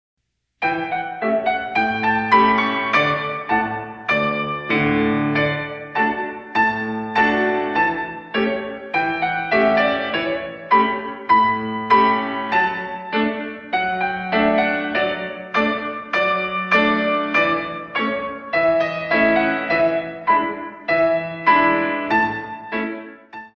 Pianist
In 2